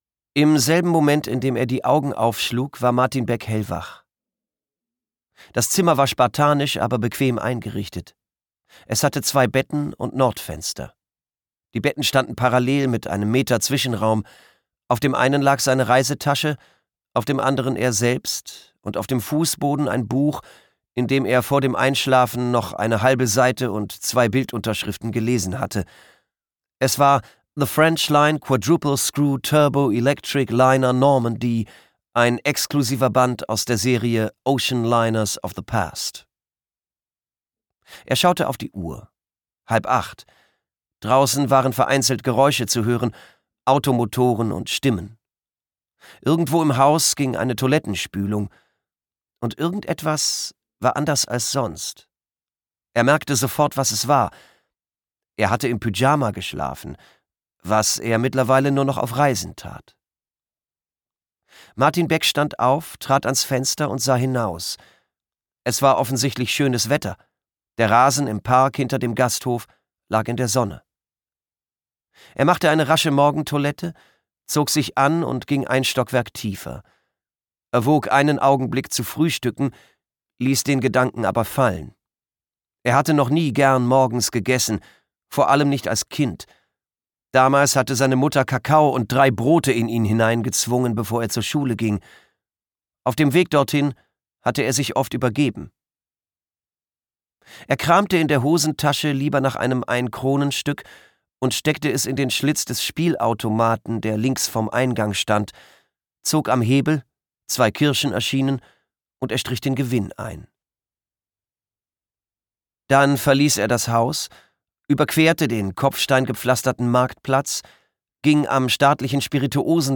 Schweden-Krimi
Gekürzt Autorisierte, d.h. von Autor:innen und / oder Verlagen freigegebene, bearbeitete Fassung.